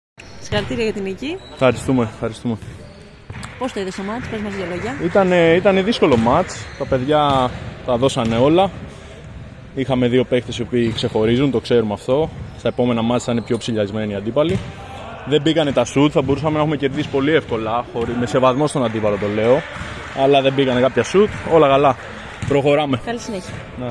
GAME INTERVIEWS:
(Παίκτης Hempel)